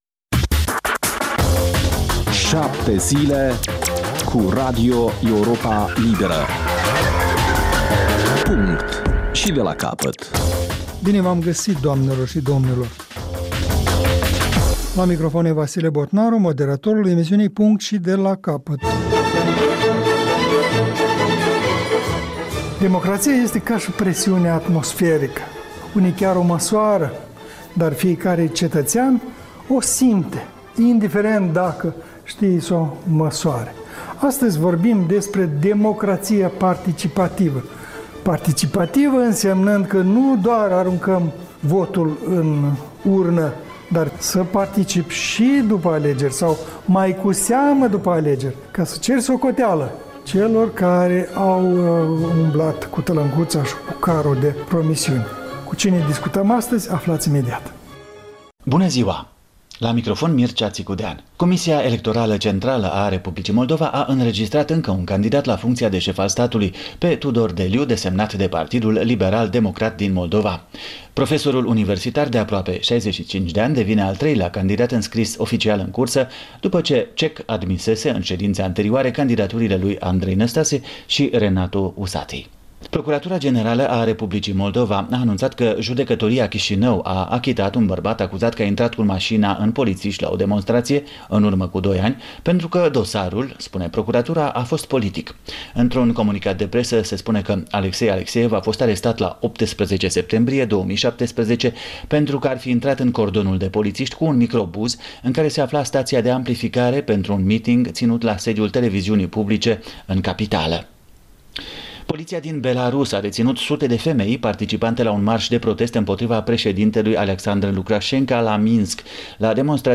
O discuţie la masa rotundă